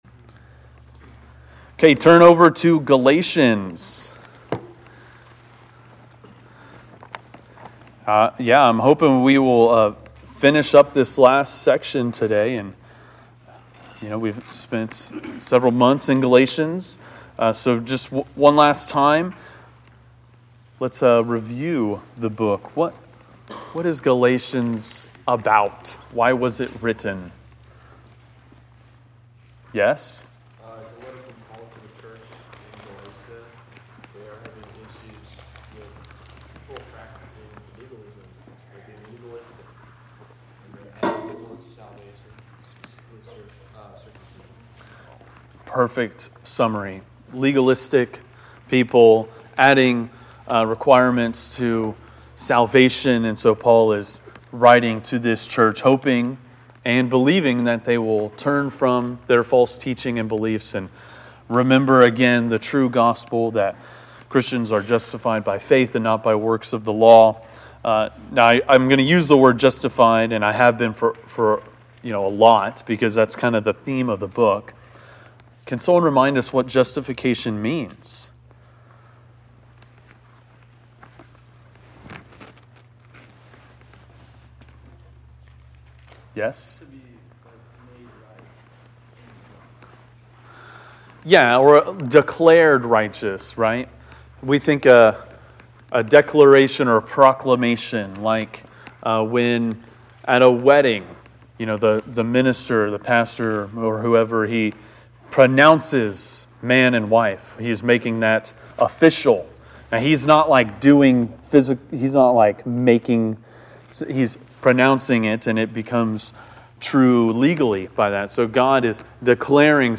Galatians 6:11-18 (Inductive Bible Study)